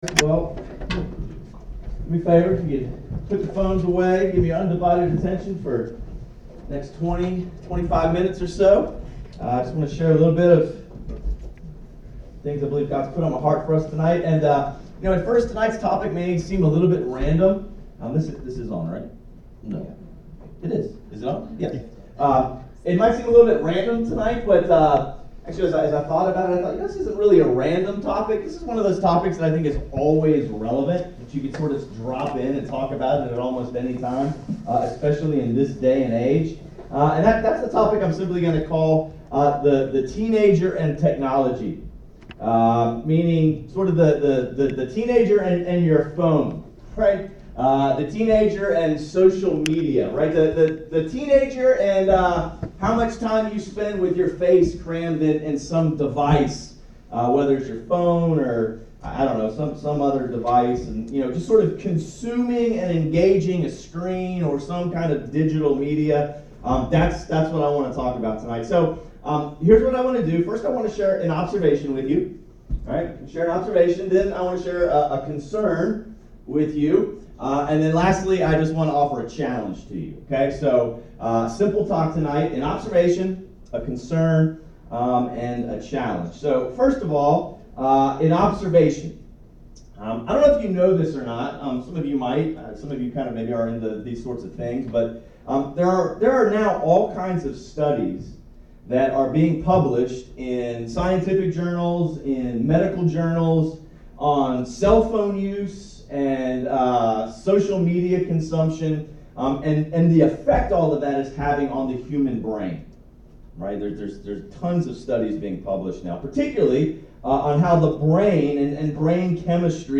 A message from the series "Called Out."